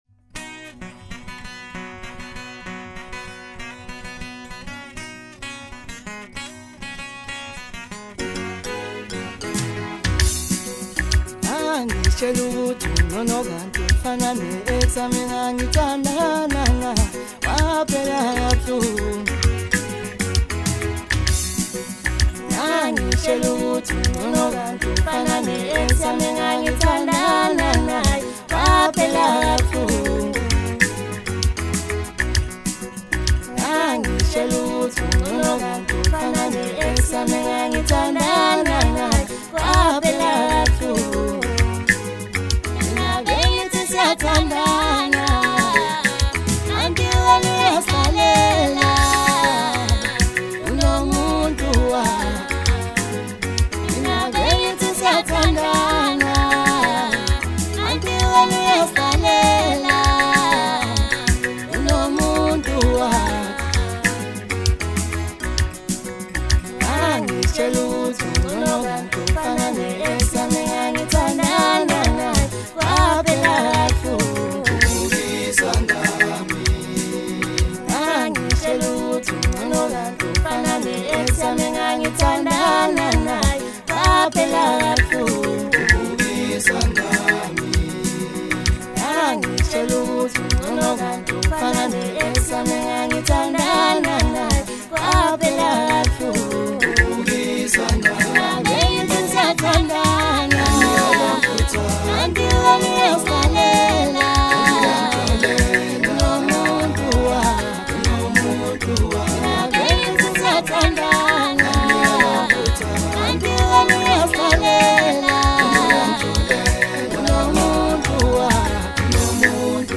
Home » Hip Hop » Latest Mix » Maskandi
South African Maskandi sound